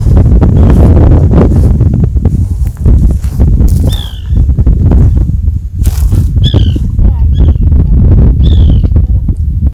White Woodpecker (Melanerpes candidus)
Country: Argentina
Province / Department: Entre Ríos
Condition: Wild
Certainty: Observed, Recorded vocal